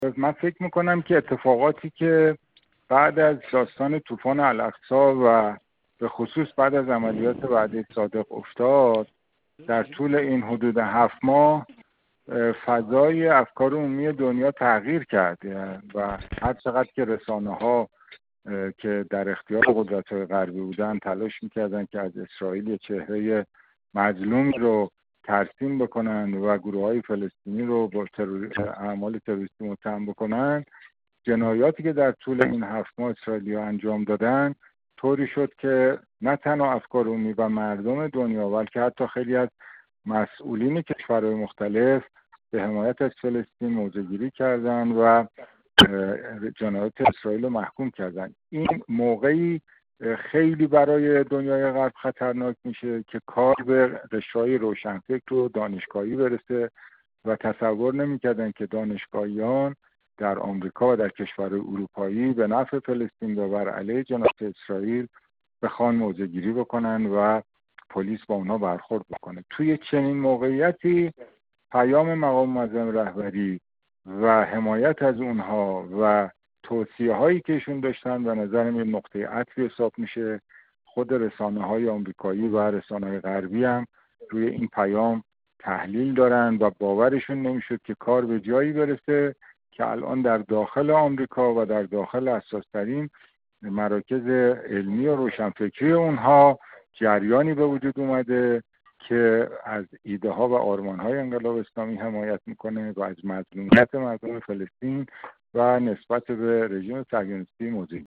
رامین مهمان‌پرست، سخنگوی اسبق وزارت امور خارجه و سفیر پیشین ایران در لهستان